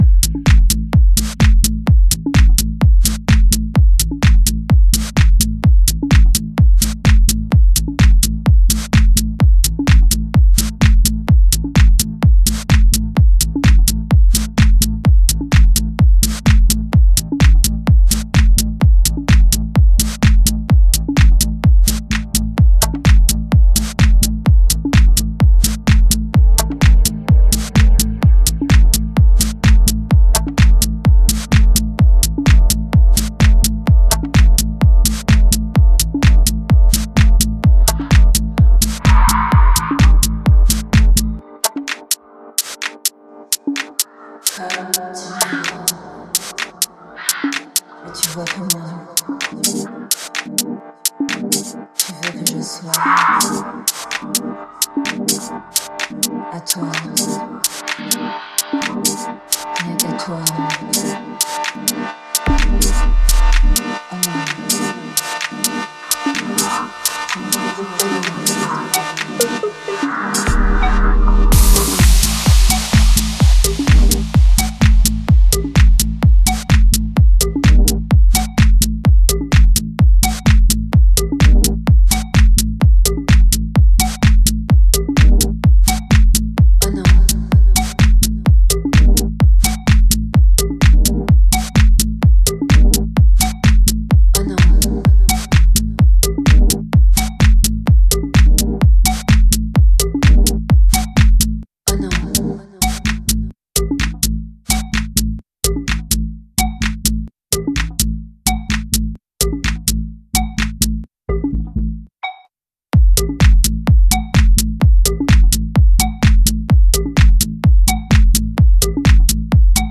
Minimal Techno